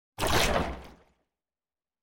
دانلود آهنگ آب 81 از افکت صوتی طبیعت و محیط
دانلود صدای آب 81 از ساعد نیوز با لینک مستقیم و کیفیت بالا
جلوه های صوتی